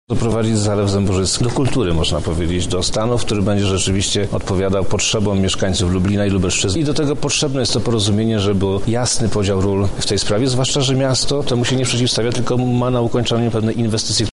Wojewoda Lubelski Przemysław Czarnek, komentuje zmianę zarządcy: